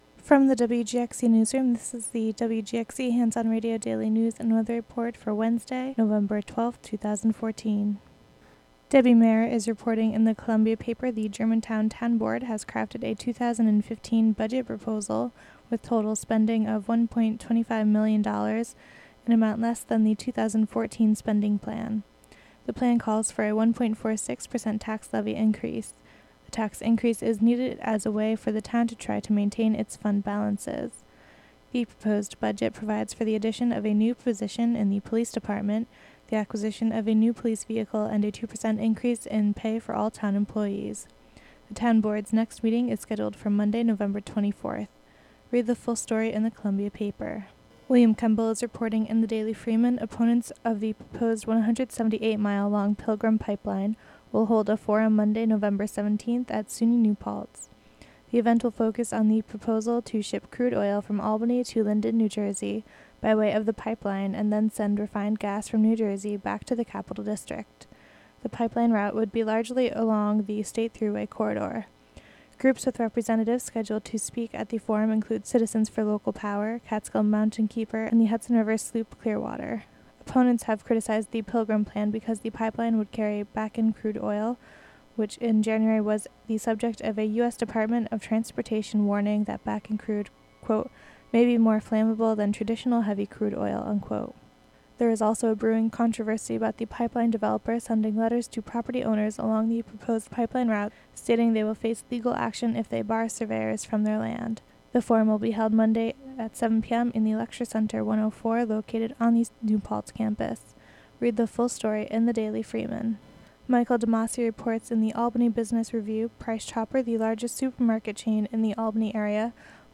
Local news and weather for Wednesday, November 12, 2014.